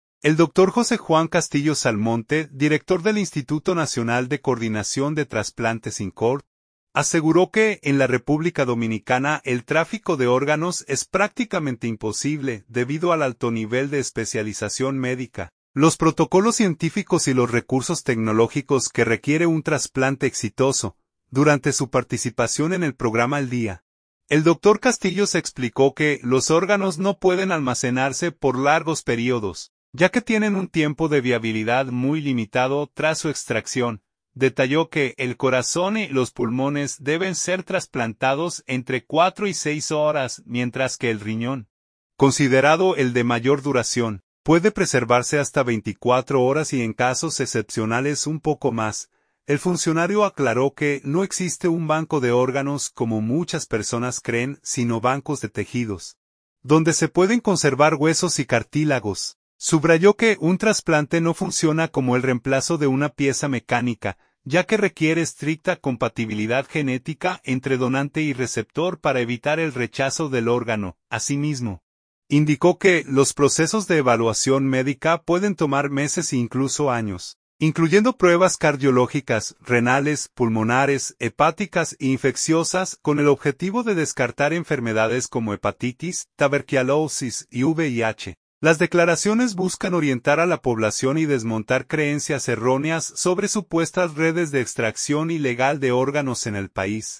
Durante su participación en el programa El Día, el doctor Castillos explicó que los órganos no pueden almacenarse por largos períodos, ya que tienen un tiempo de viabilidad muy limitado tras su extracción.